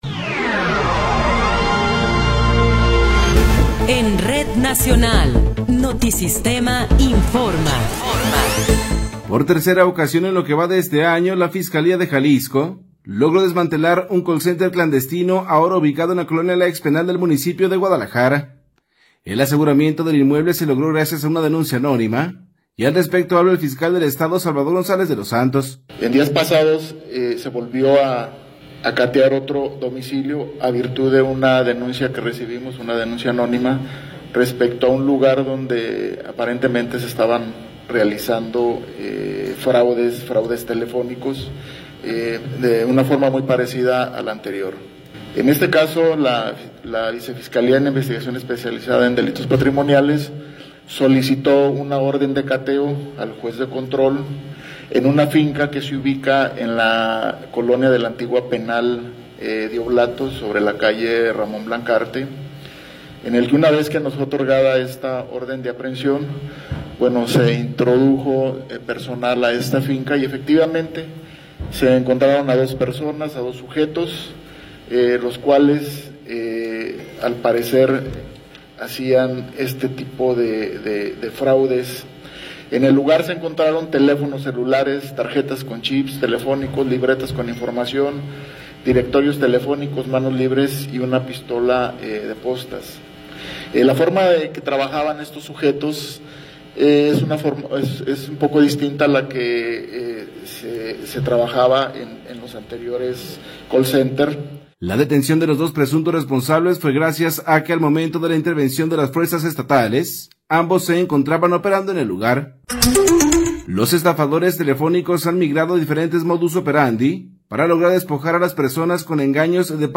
Noticiero 12 hrs. – 19 de Abril de 2026
Resumen informativo Notisistema, la mejor y más completa información cada hora en la hora.